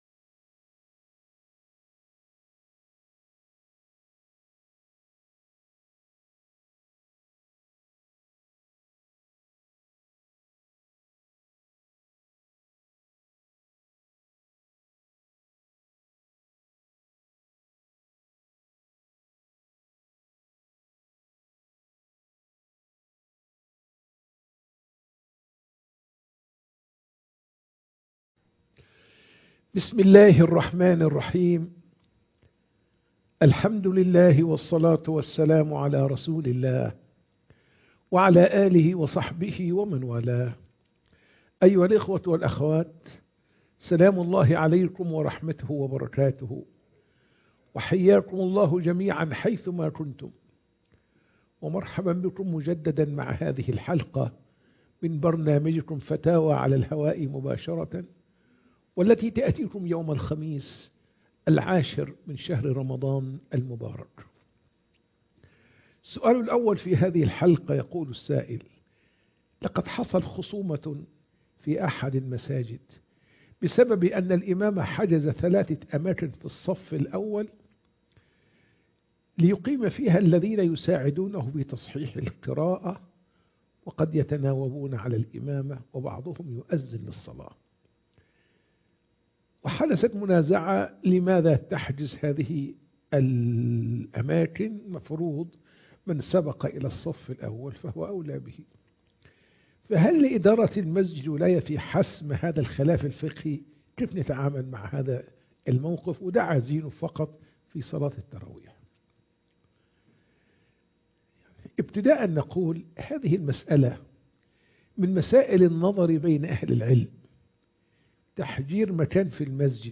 فتاوى على الهواء